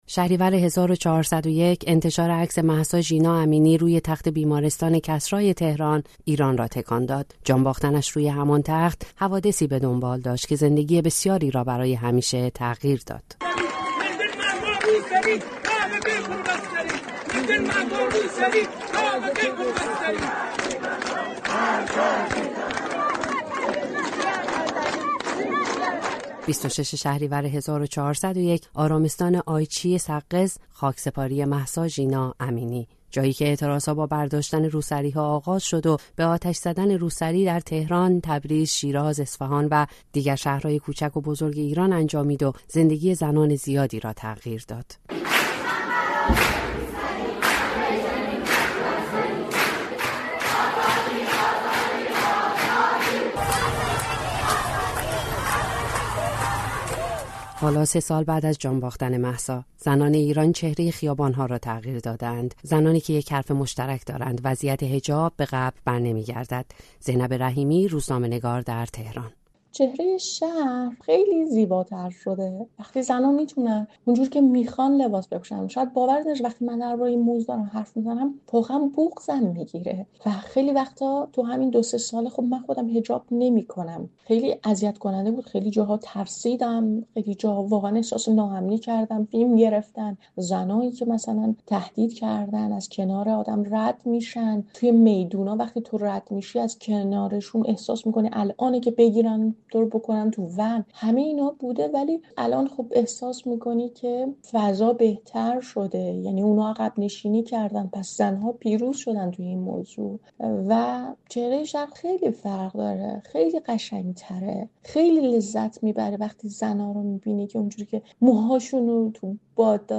زنانی که بدون حجاب اجباری به خیابان‌ها می‌آیند، می‌گویند به عقب برنمی‌گردند. در این گزارش ویژه با زنانی حرف زده‌ایم که با افتخار از دستاوردهای خود می‌گویند و تاکید دارند دیواری که ترک خورده بود بعد از مهسا فروریخت.